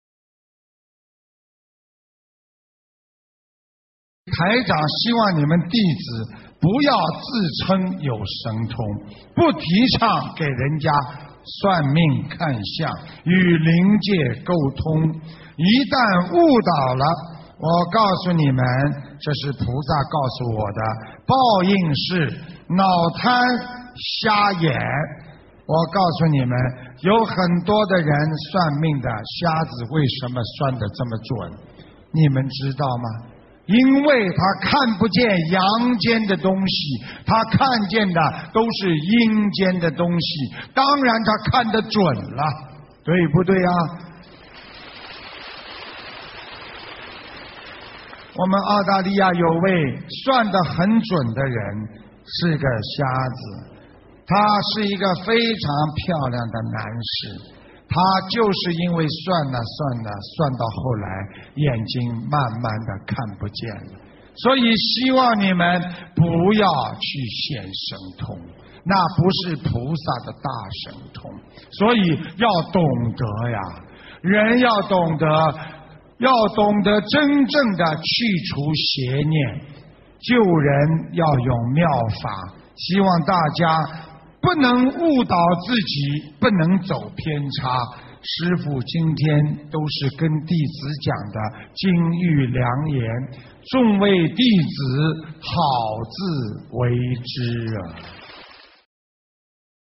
2013年6月9日香港法会【师父开示原音】